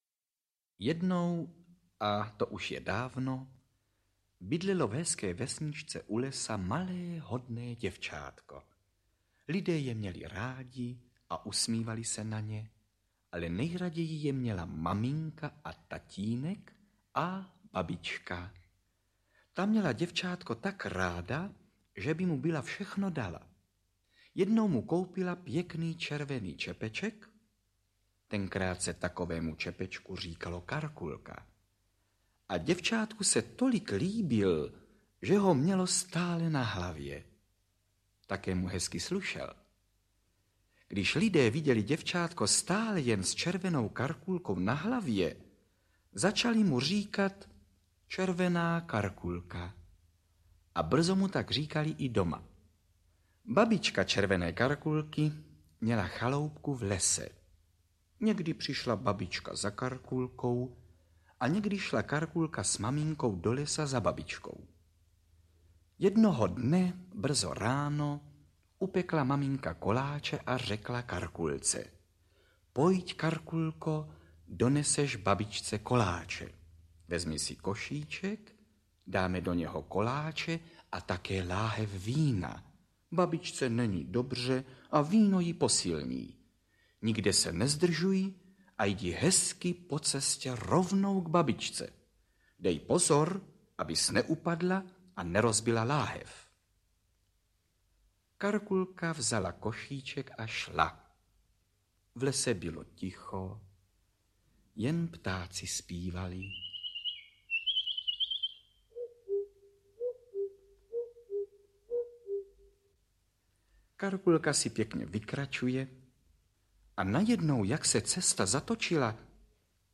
Červená Karkulka a sedm dalších pohádek audiokniha
Audio kniha